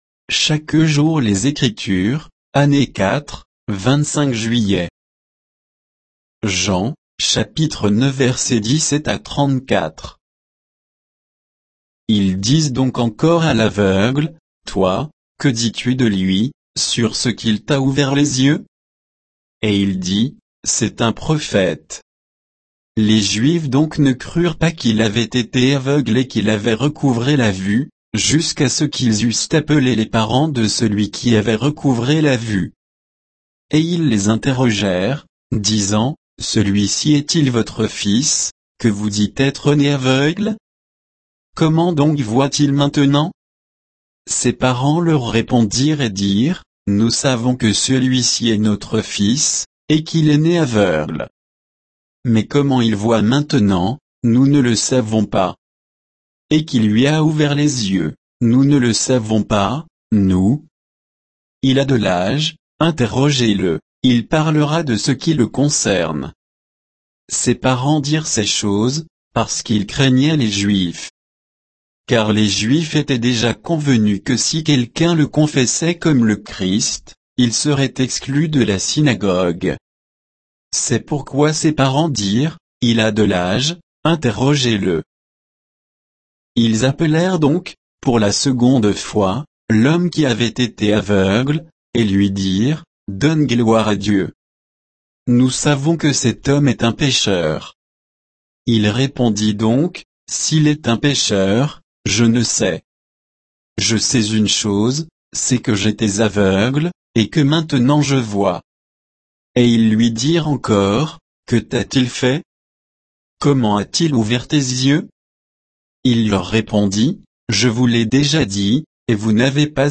Méditation quoditienne de Chaque jour les Écritures sur Jean 9, 17 à 34